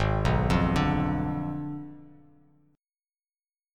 G#dim7 chord